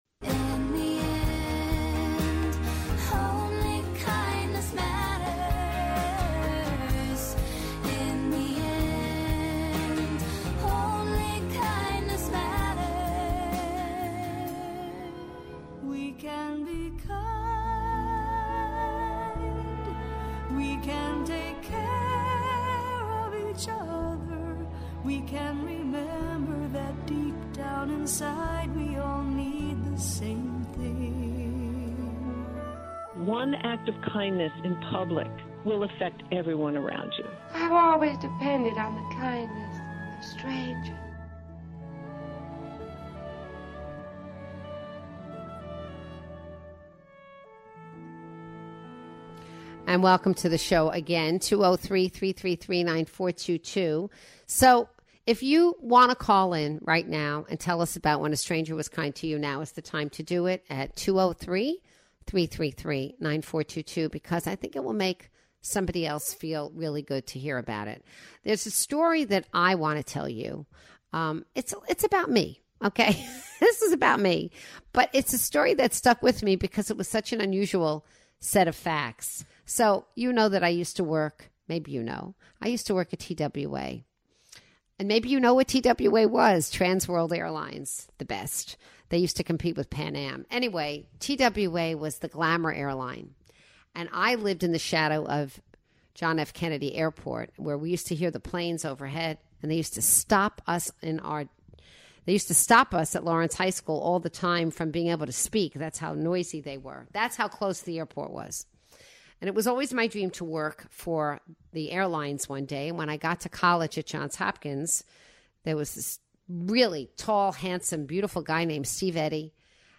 takes your calls about times a stranger showed you an act of kindness.